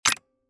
STD_button_TotalBet_Up.mp3